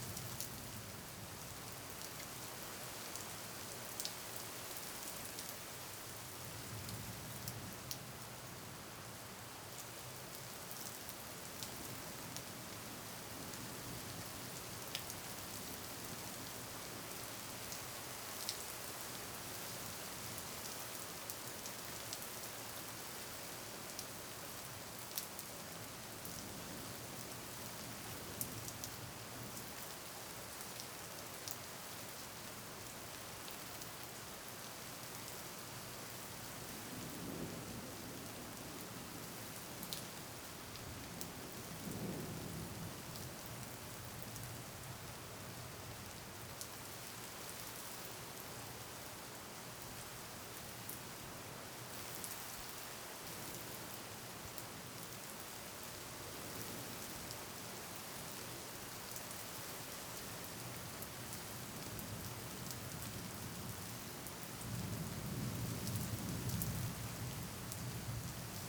Rain and Thunder
Weather Evening Light Rain Thunder Rustling Trees ST450 03_ambiX.wav